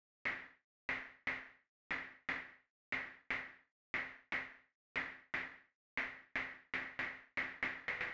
28 ElPercussion.wav